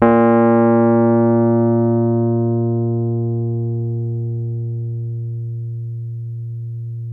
RHODES CL06L.wav